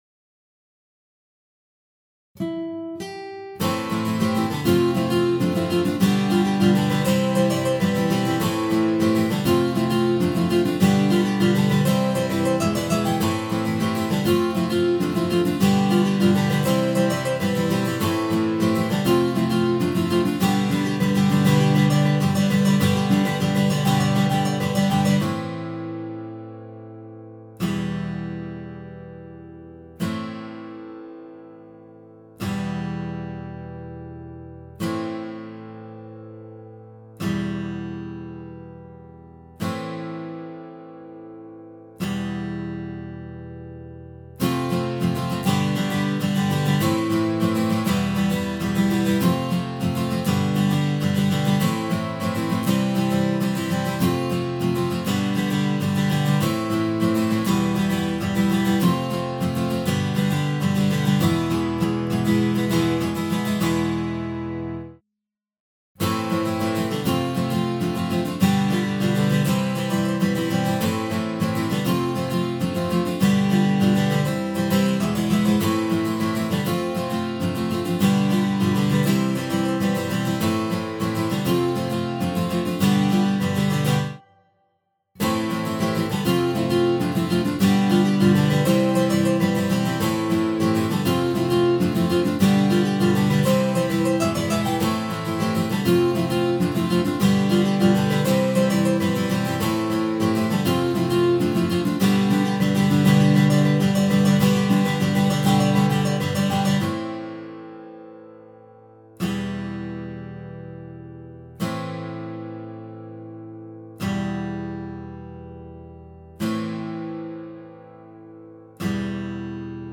カラオケ音源 bpm100